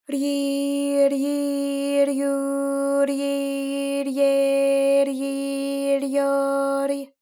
ALYS-DB-001-JPN - First Japanese UTAU vocal library of ALYS.
ryi_ryi_ryu_ryi_rye_ryi_ryo_ry.wav